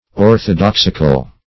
Search Result for " orthodoxical" : The Collaborative International Dictionary of English v.0.48: Orthodoxical \Or`tho*dox"ic*al\, a. Pertaining to, or evincing, orthodoxy; orthodox.